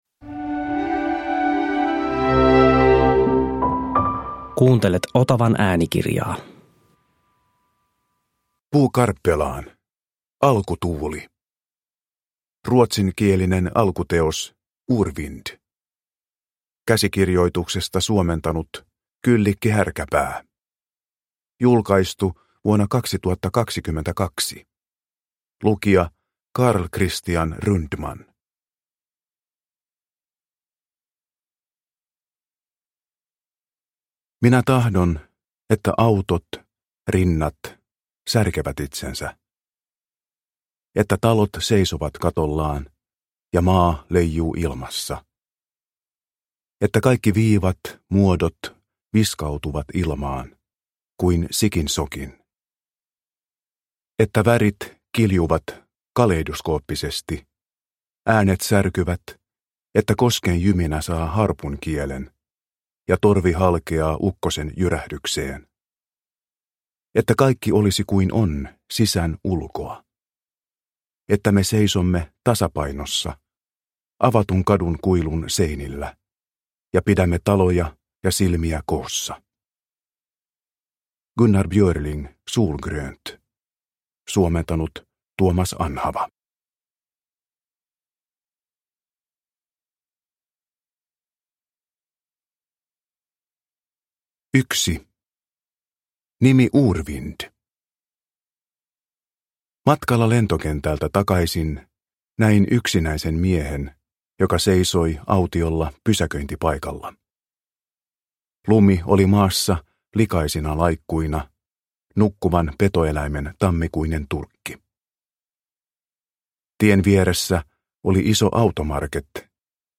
Alkutuuli – Ljudbok – Laddas ner